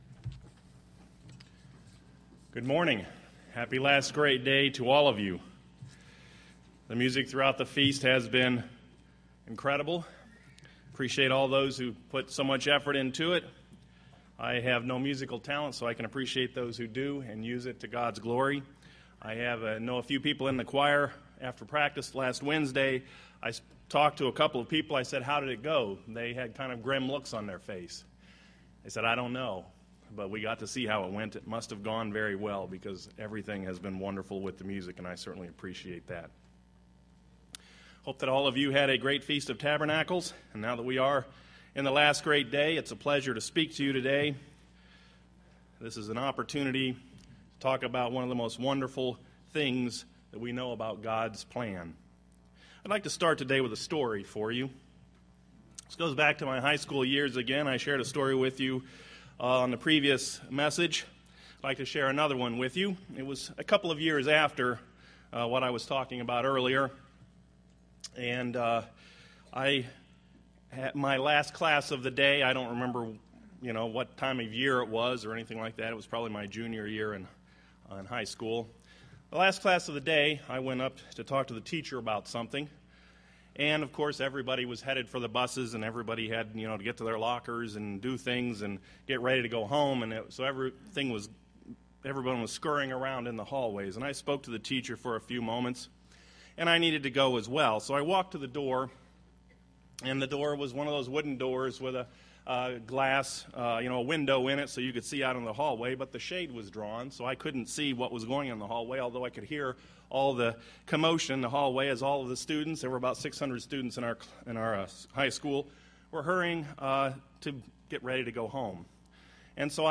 This sermon was given at the Pigeon Forge, Tennessee 2014 Feast site.